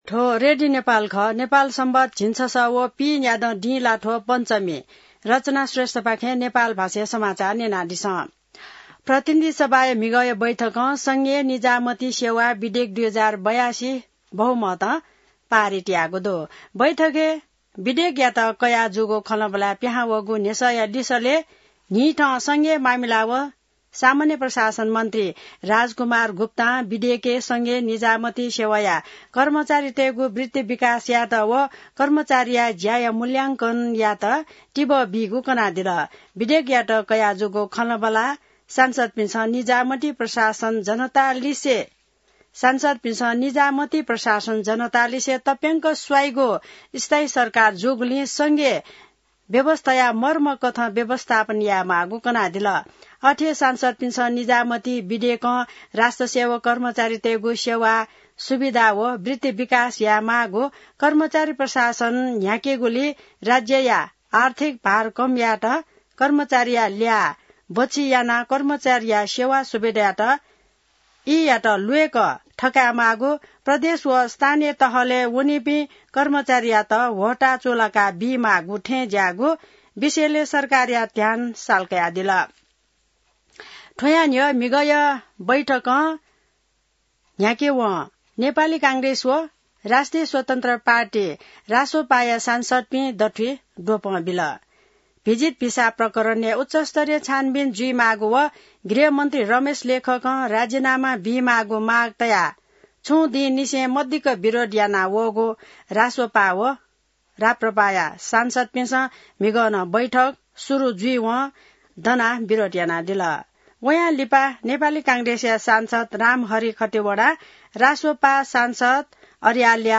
नेपाल भाषामा समाचार : १६ असार , २०८२